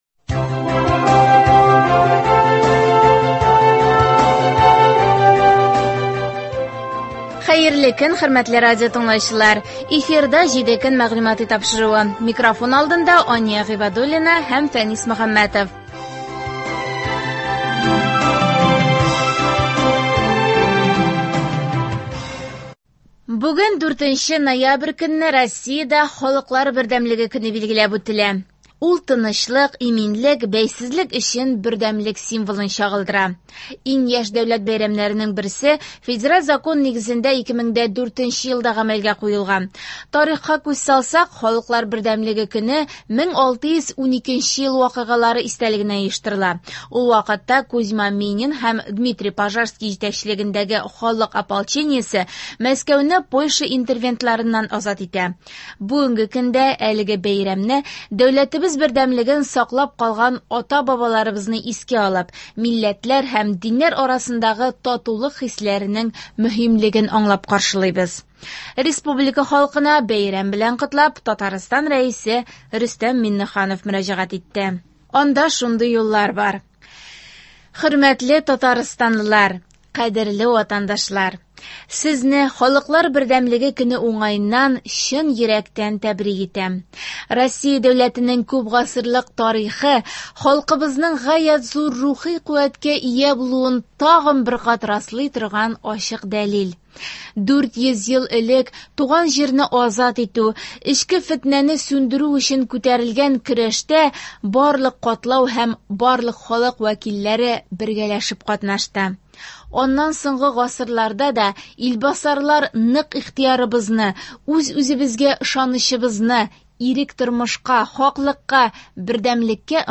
Атналык күзәтү.